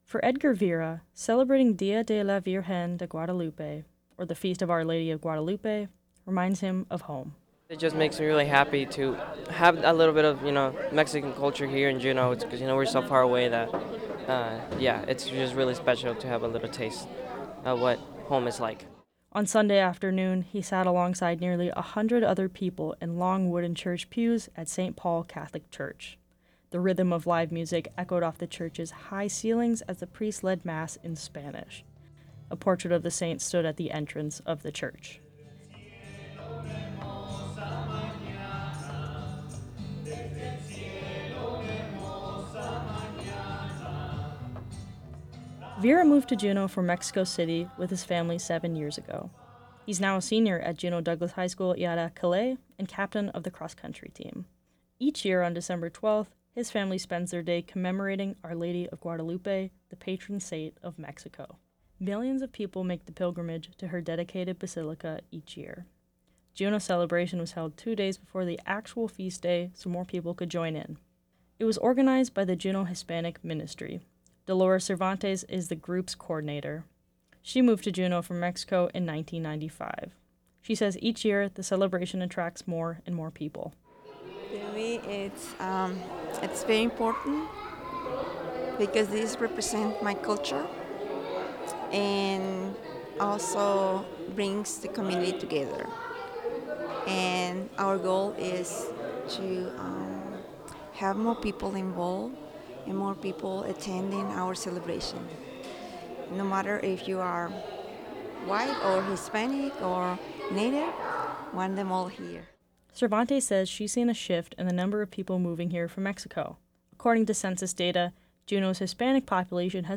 The rhythm of live music echoed off the high ceilings and played intermittently as the priest led Mass in Spanish.